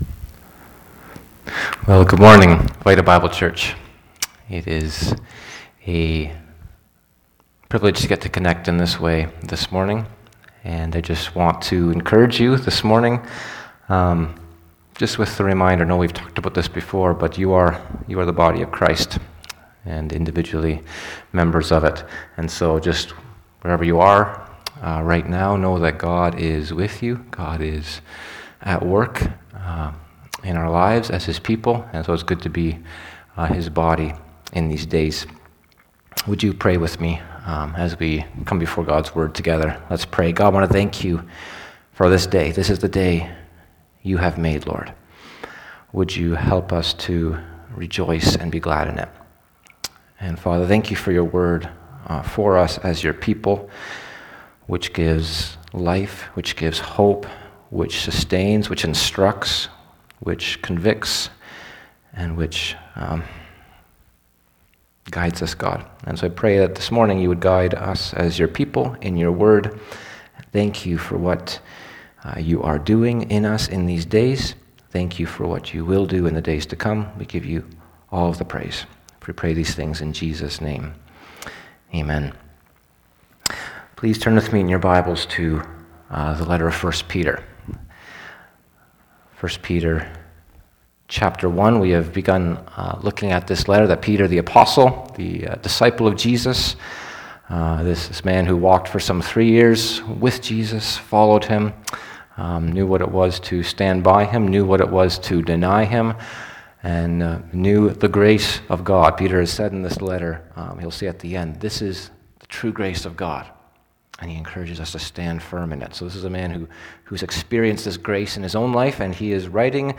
Posted in Sermons .